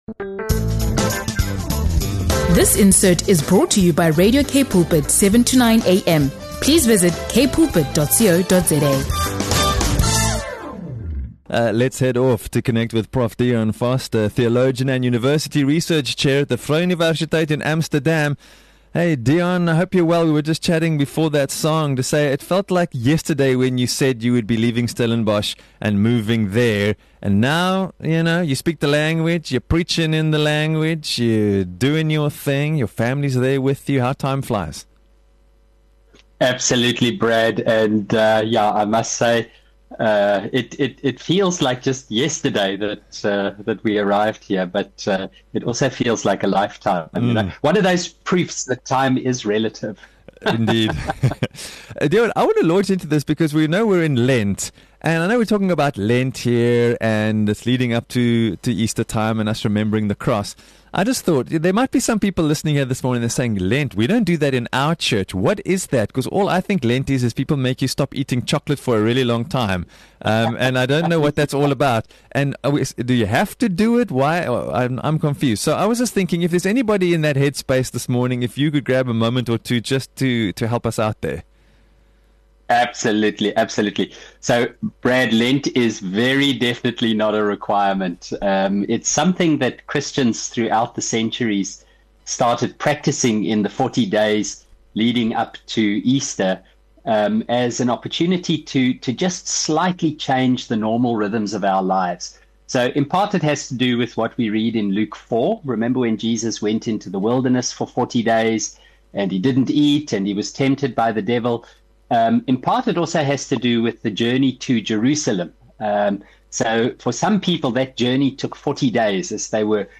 The conversation also explores the powerful biblical call to become peacemakers, based on Matthew 5:9, and what that looks like in a world filled with conflict and division.